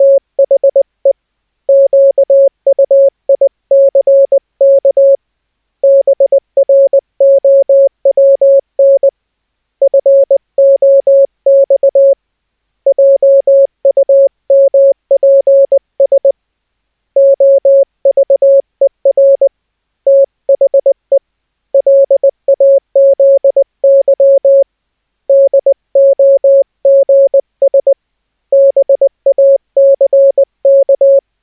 La velocità di trasmissione è 20 wpm (parole al minuto), corrispondenti a 100 caratteri al minuto.
Probabilmente si tratta di riverbero naturale, presumo quindi che solo questi operatori abbiano registrato i suoni prodotti dall'altoparlante attraverso un microfono.
Op D 550 Hz.mp3